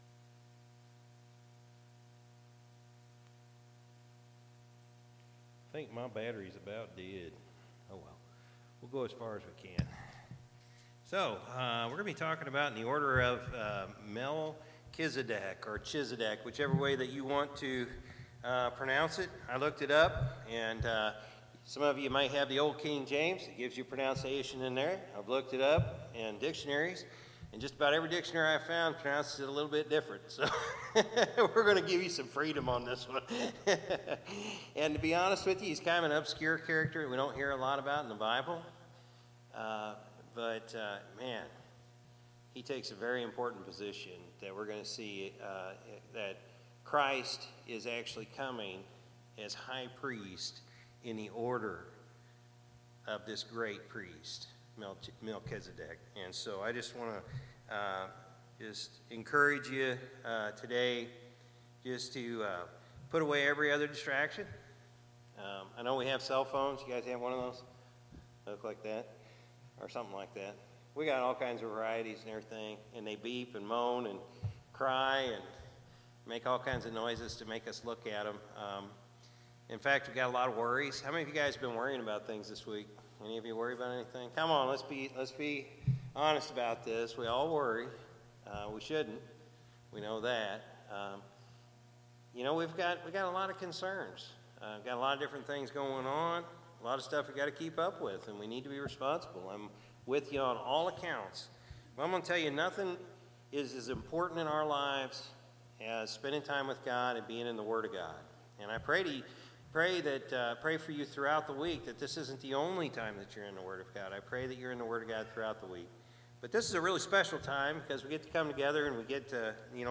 June-4-2023-Morning-Service.mp3